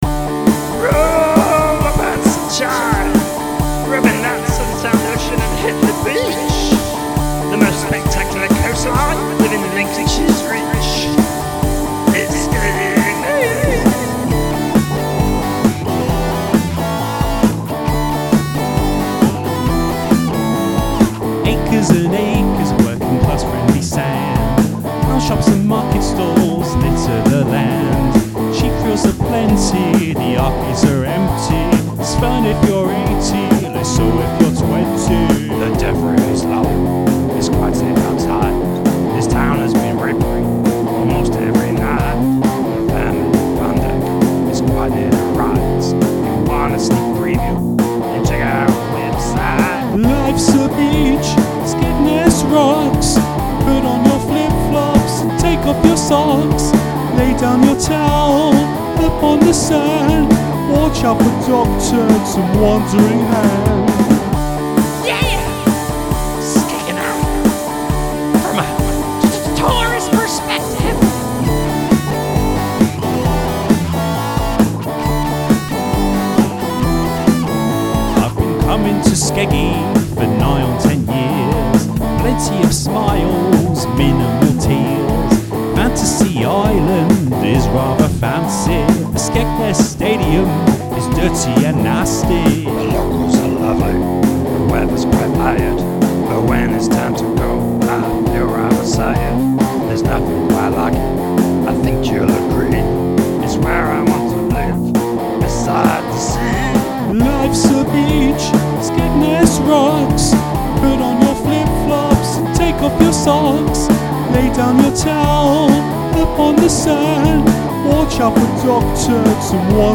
There are perspectives from tourists and locals